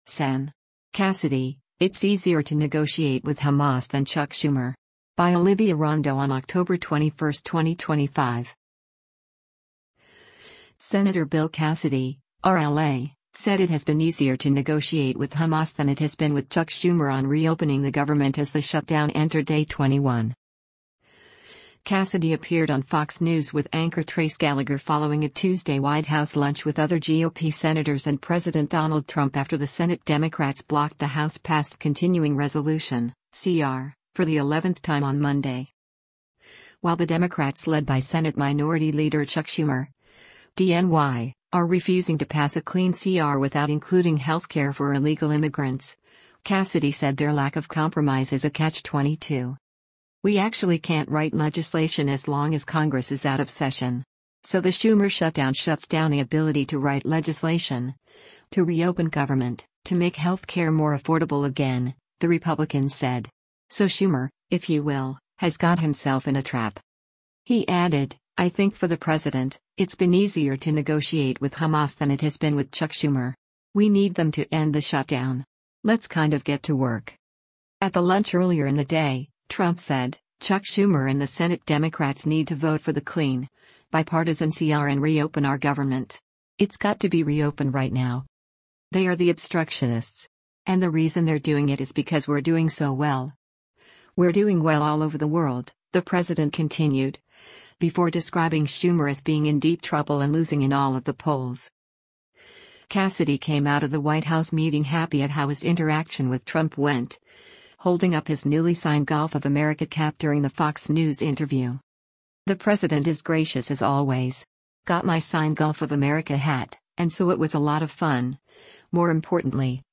Cassidy appeared on Fox News with anchor Trace Gallagher following a Tuesday White House lunch with other GOP senators and President Donald Trump after the Senate Democrats blocked the House-passed continuing resolution (CR) for the eleventh time on Monday.